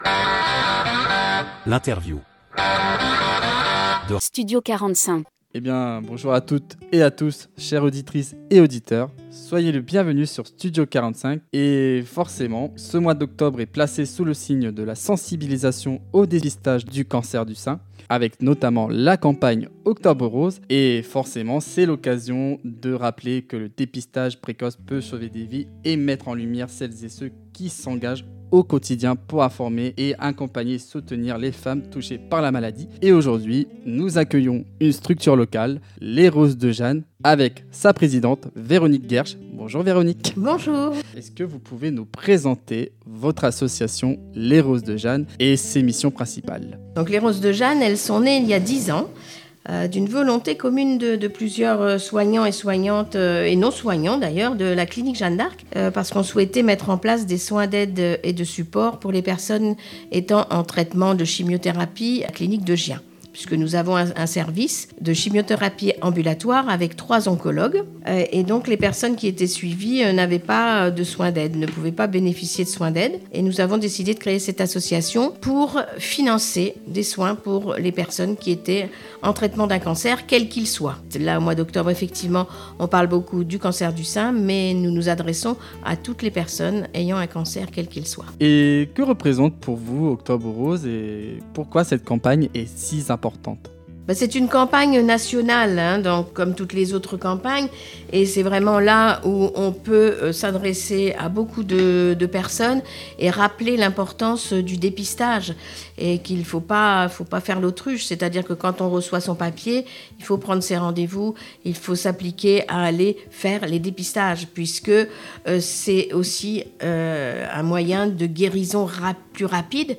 Interview Studio 45 - Les Roses de Jeanne